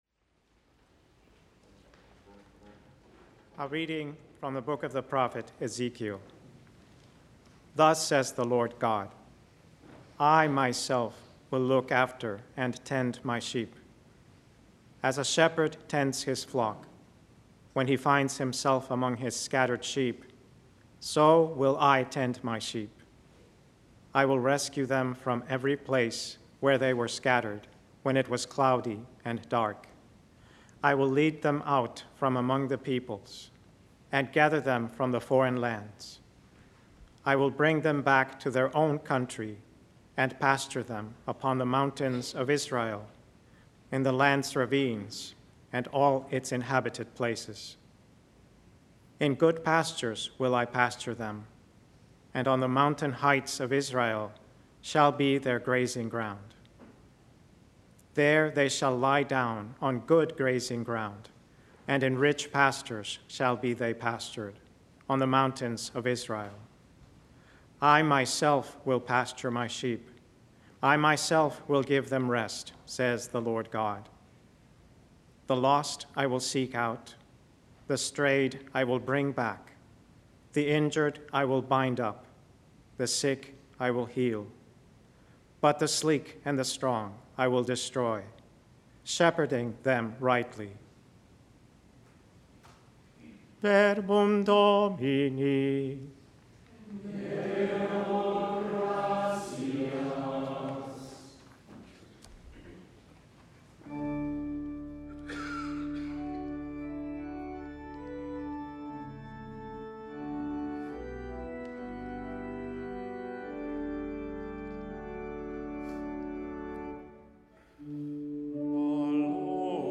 Readings, Homily And Daily Mass